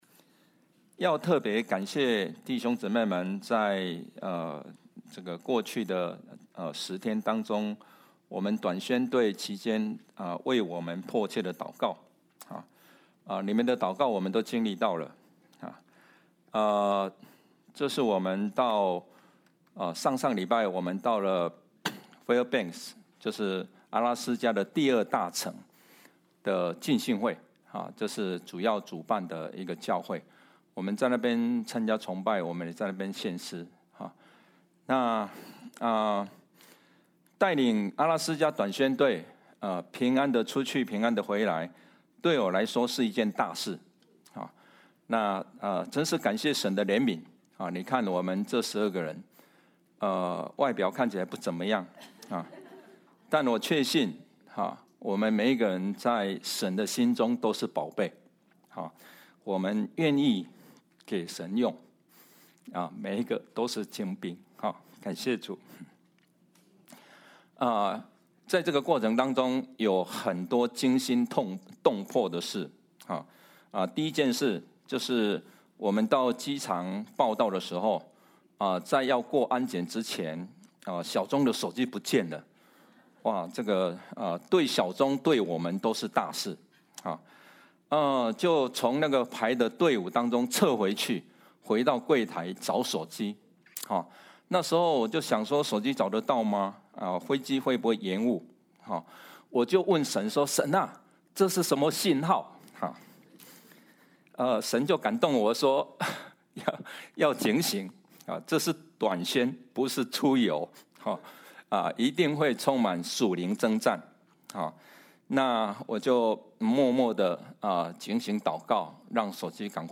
Passage: Romans, Chapter 5 Service Type: 主日证道 Download Files Notes « 榮耀上帝聖名 天人交战 » Submit a Comment Cancel reply Your email address will not be published.